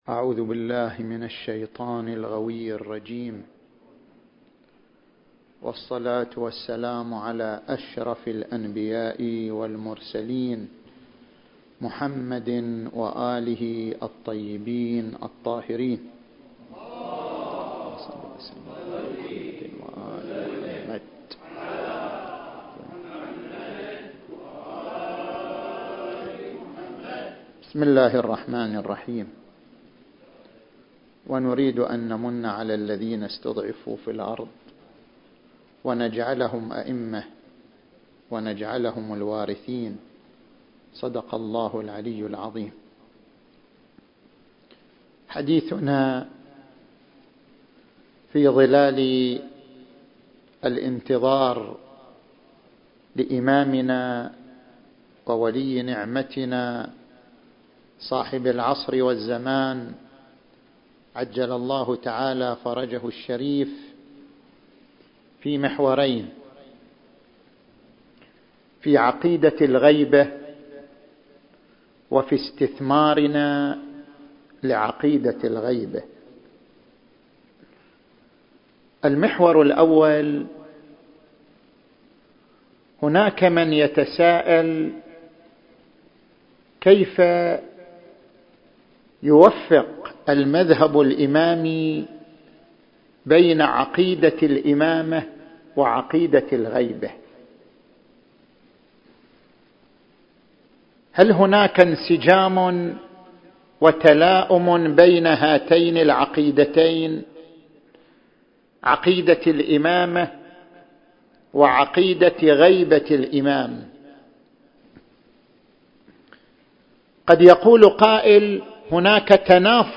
المكان: حسينية ومصلى الإمام المهدي (عجّل الله فرجه) - أم الحمام التاريخ: 1442